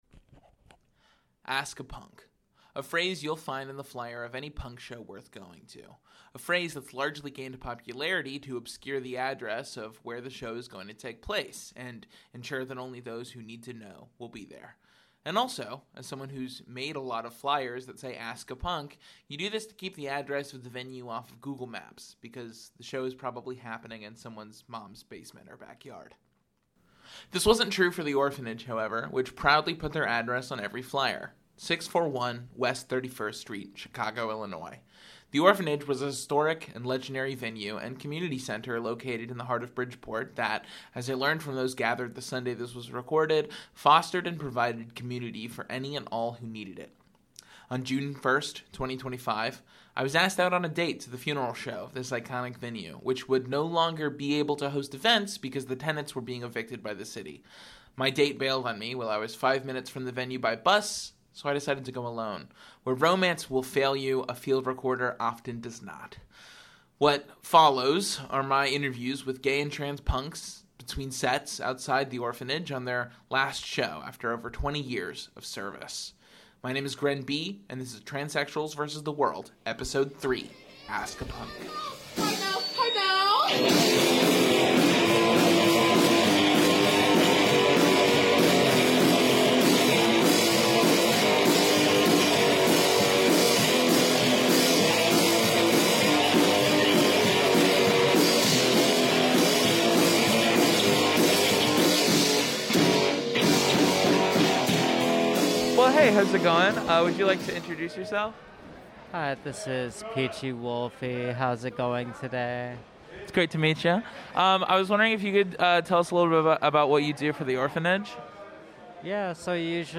Where romance will fail you, a field recorder often does not.